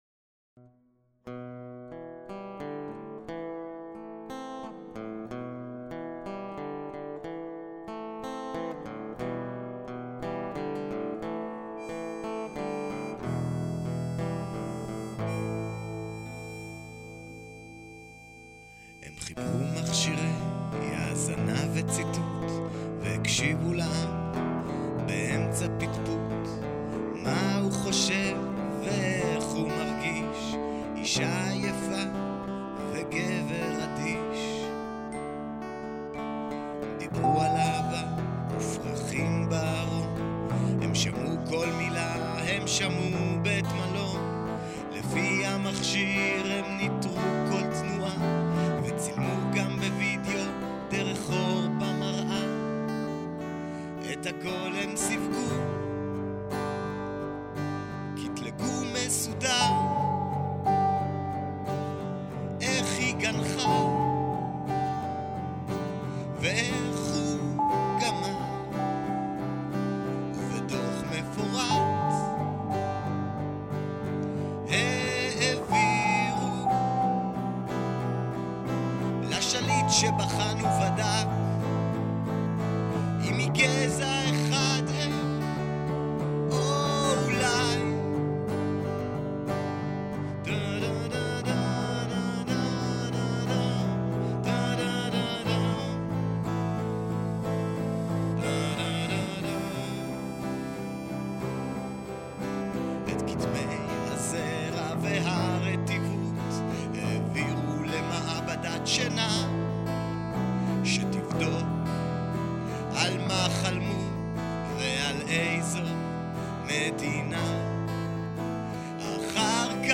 לשמיעת והורדת השיר בהקלטה בייתית ליחצו כאן